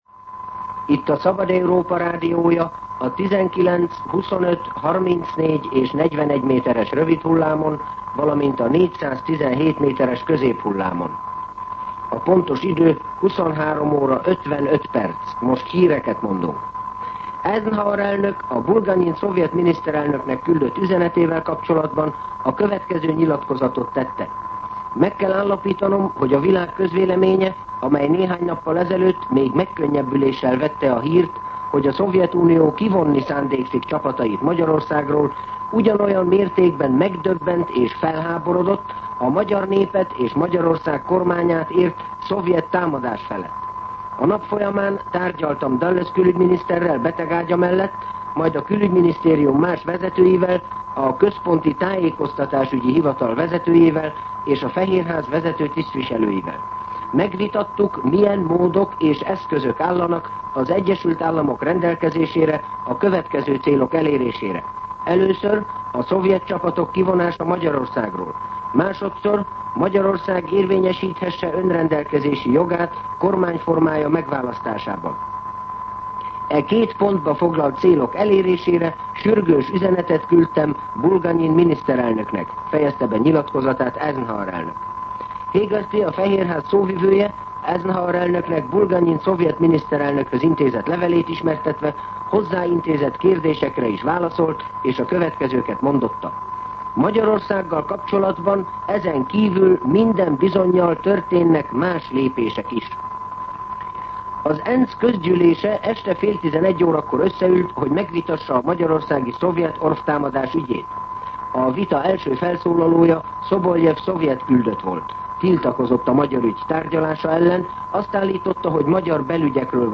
23:55 óra. Hírszolgálat